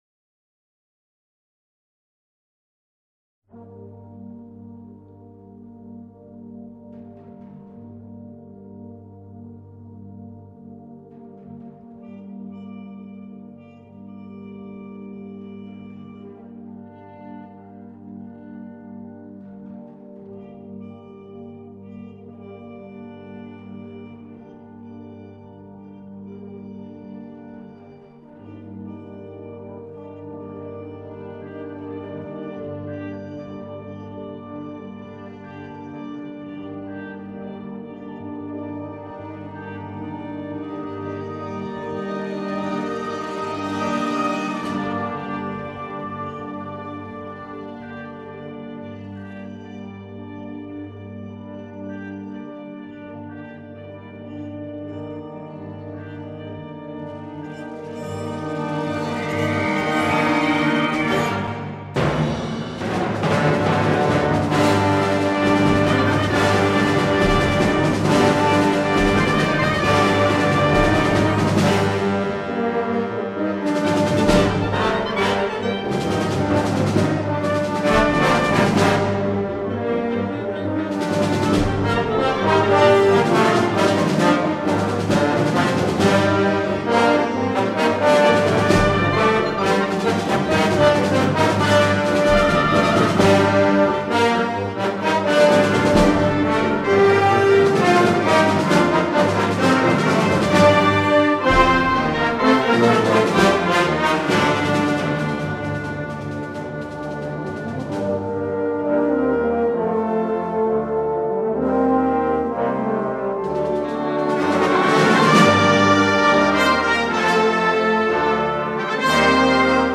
for Wind Band